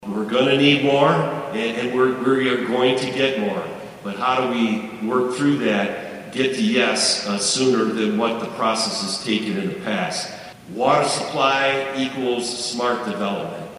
spoke to a gathering at Tuesday’s conference in Manhattan